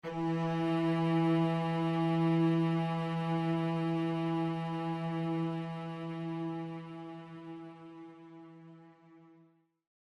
Нота: Ми первой октавы (E4) – 329.63 Гц
Note4_E4.mp3